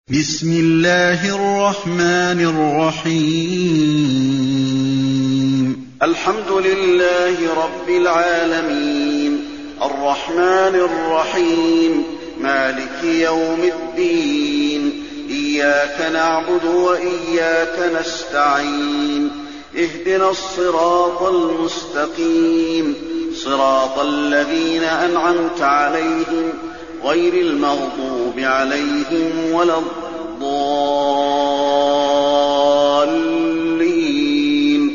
المكان: المسجد النبوي الفاتحة